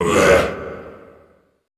Index of /90_sSampleCDs/Best Service - Extended Classical Choir/Partition I/DEEP SHOUTS
DEEP UAE 2-R.wav